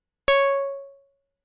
Roland Juno 6 Pulse Bass " Roland Juno 6 Pulse Bass C6 ( Pulse Bass85127)
Tag: MIDI-速度-96 CSharp6 MIDI音符-85 罗兰朱诺-6 合成器 单票据 多重采样